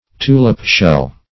Search Result for " tulip-shell" : The Collaborative International Dictionary of English v.0.48: Tulip-shell \Tu"lip-shell`\, n. (Zool.)